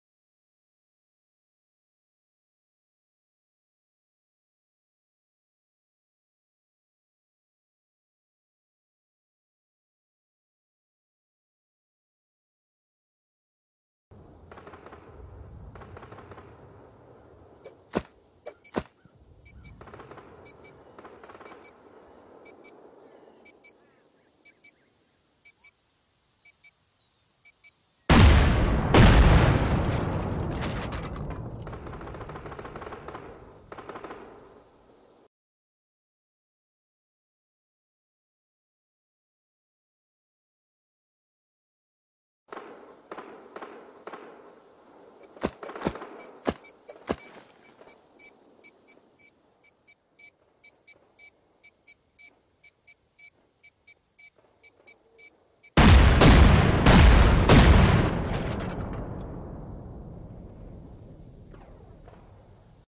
Rust C4 Sound! (for trolls 2016!)